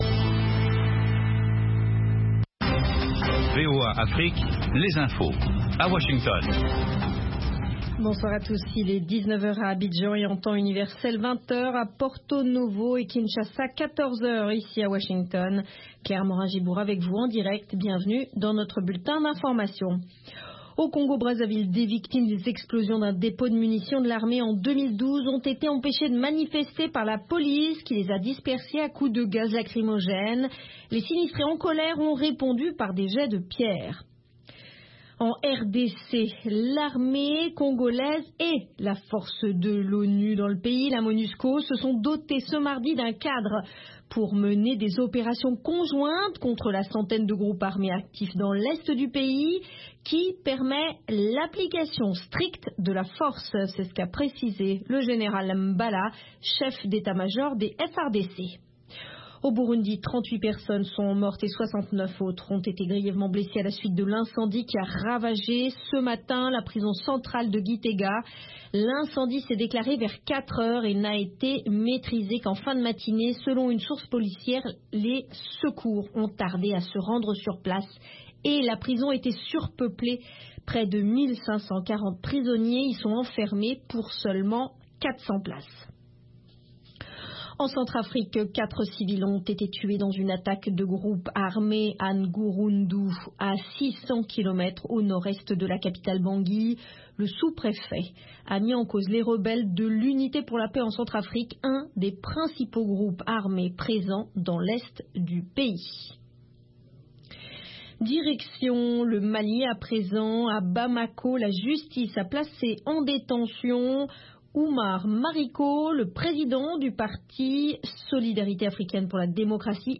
3 min Newscast